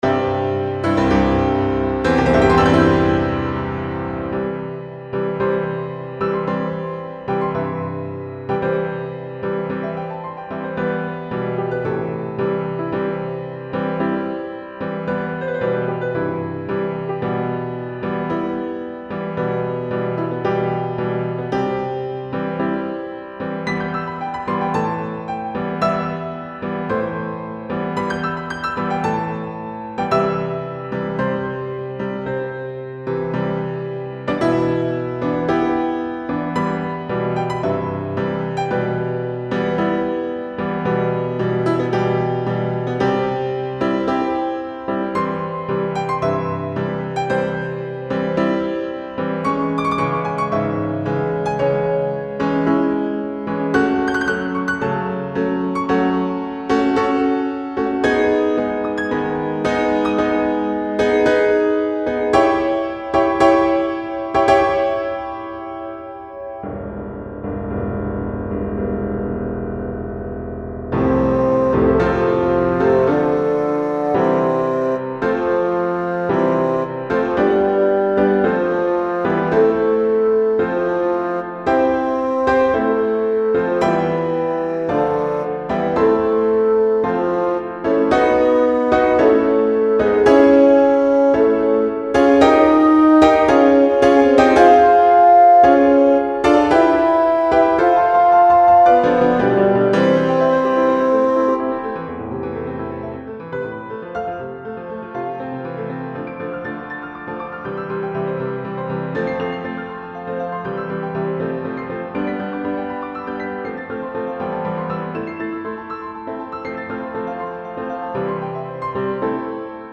Tonart: C-Dur / Tempo: Allegro moderato quasi Andante
Natur und Kultur; Fjord und Hochzeit; Unterschiede und doch Gemeinsamkeiten. - In der Demoaufnahme wurde die Gesangstimme durch ein Fagott ersetzt.